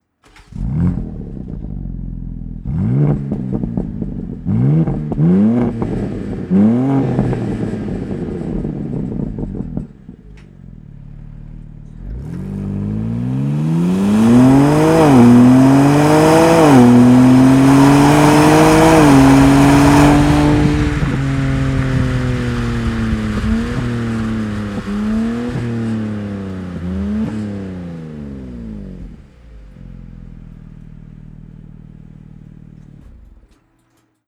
• Exhaust Audio — Stock
STOCK_EXHAUST_SYSTEM_BMW_M2_COUPE_G87.wav